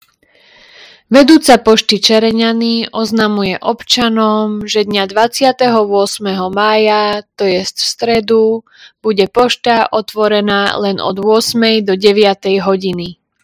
Hlásenie obecného rozhlasu – Pošta Čereňany – zmena otváracích hodín 28.05.2025